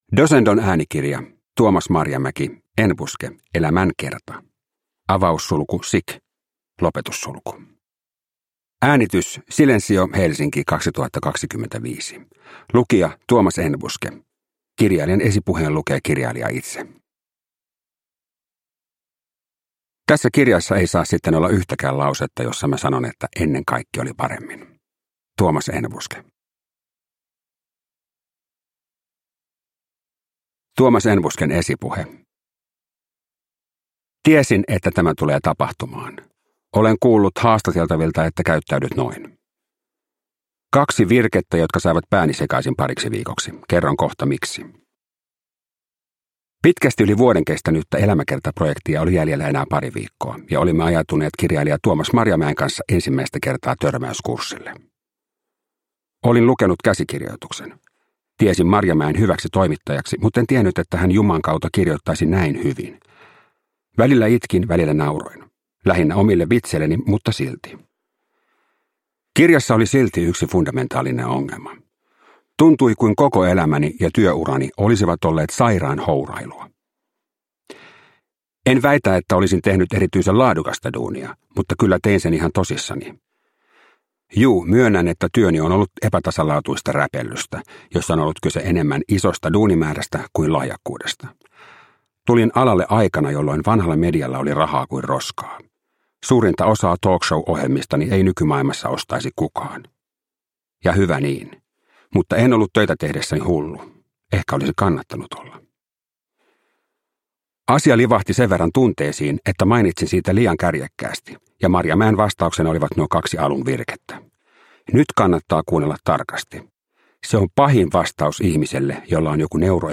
Enbuske – Elämänkerta [sic] (ljudbok) av Tuomas Marjamäki